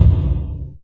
JJPercussion (5).wav